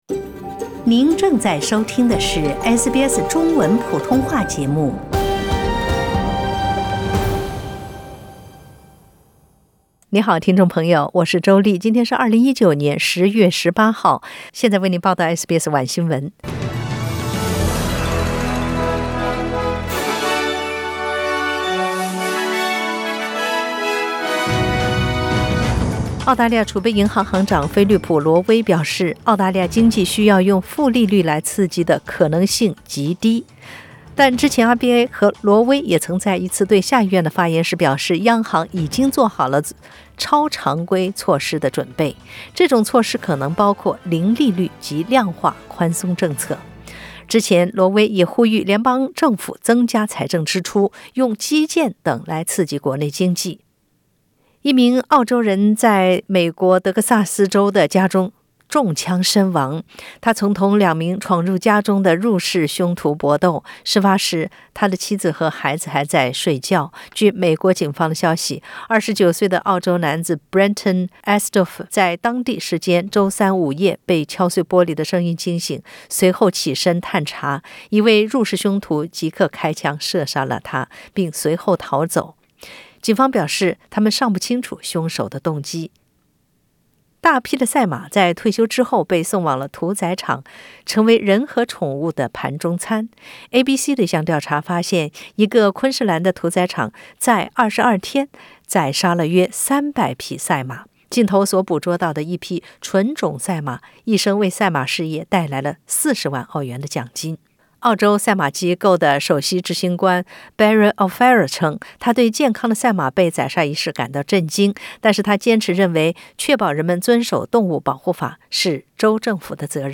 SBS 晚新闻 （10月18日）